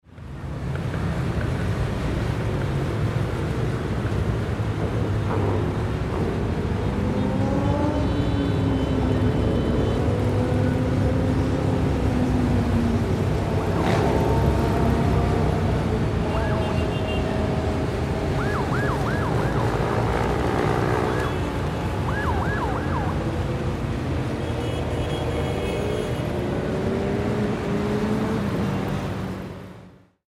Bikers Riding Slowly Through City Streets Sound
A group of bikers rides slowly through a busy city street on powerful motorcycles. Their deep engine roars fill the air, capturing a dramatic urban atmosphere. A police siren wails from a trailing patrol car, adding tension and cinematic impact.
Bikers-riding-slowly-through-city-street-sound-effect.mp3